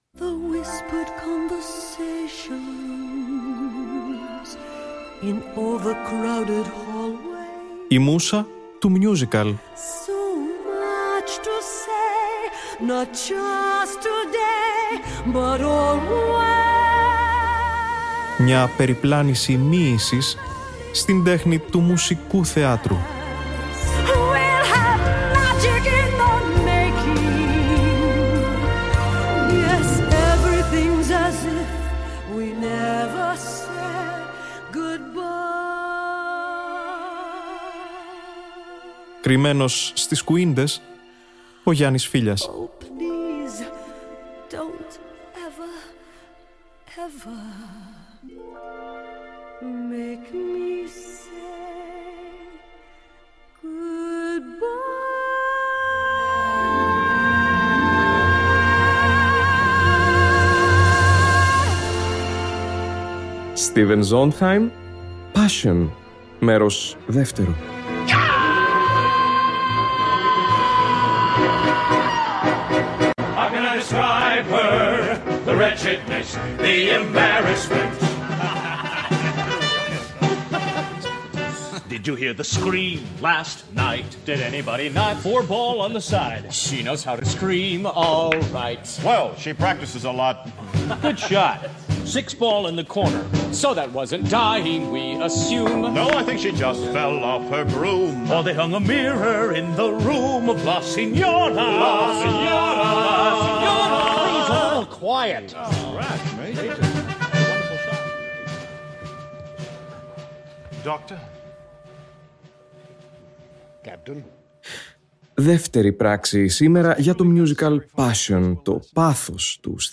Όπως και την προηγούμενη εβδομάδα, ακούμε τη ζωντανή ηχογράφηση του original Broadway cast.